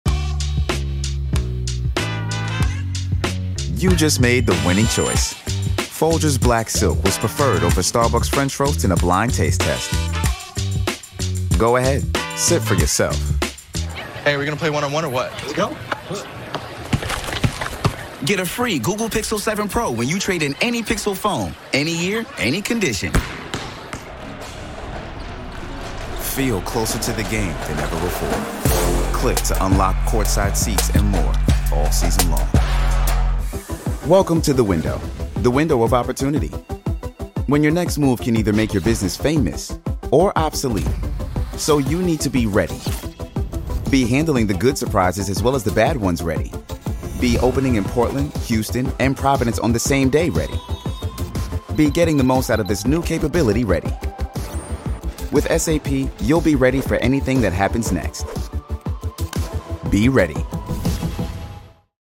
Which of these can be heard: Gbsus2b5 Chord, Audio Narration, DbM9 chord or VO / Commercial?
VO / Commercial